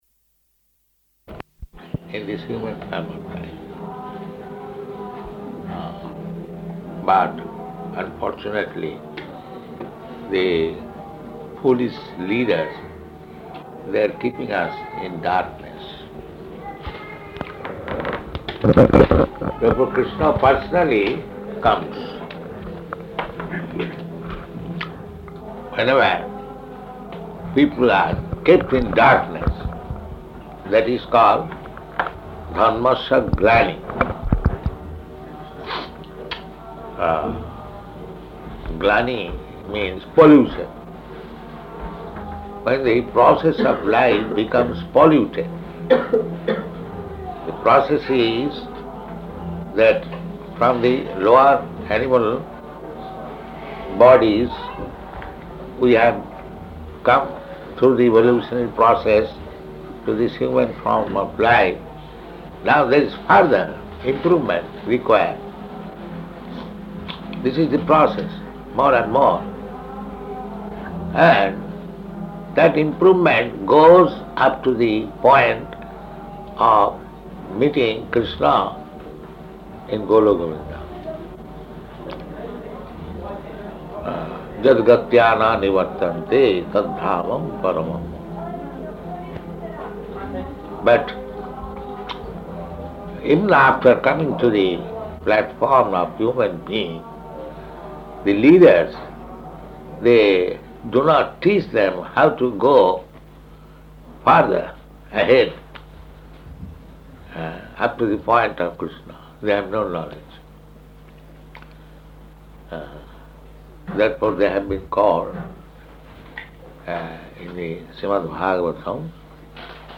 Arrival Address
Type: Lectures and Addresses
Location: Auckland, New Zealand